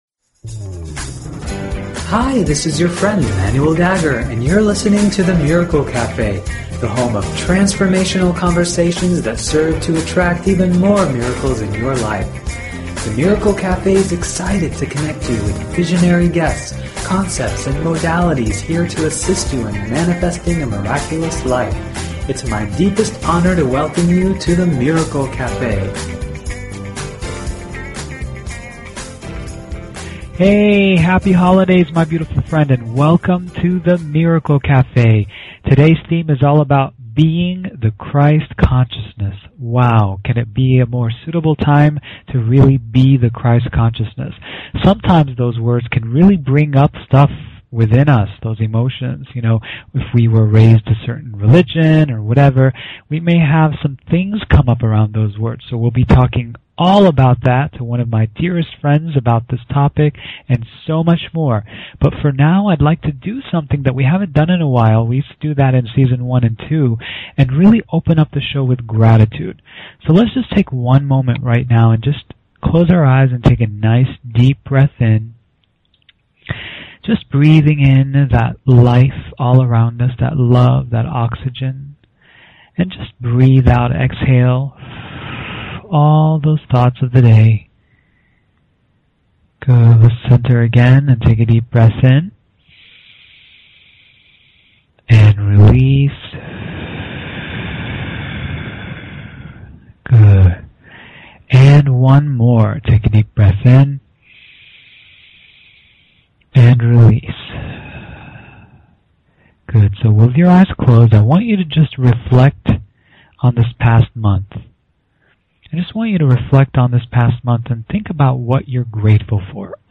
Talk Show Episode, Audio Podcast, The_Miracle_Cafe and Courtesy of BBS Radio on , show guests , about , categorized as